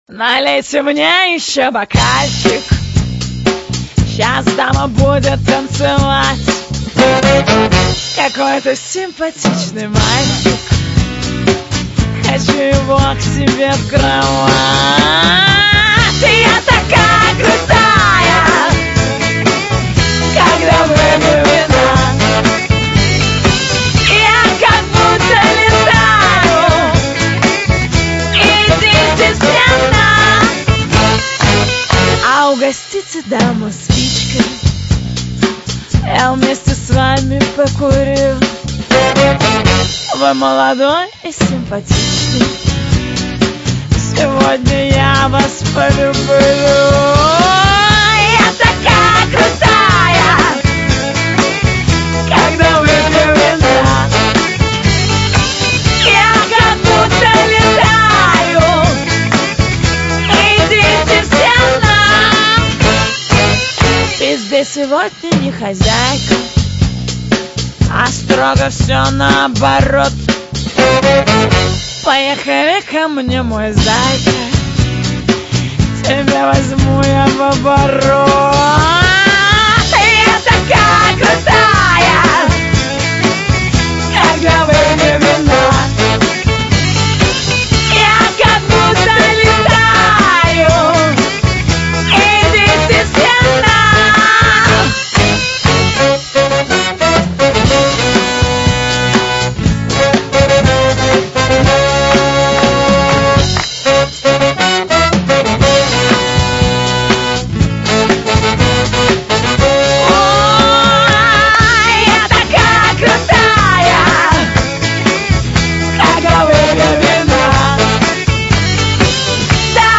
Файл в обменнике2 Myзыкa->Русский рок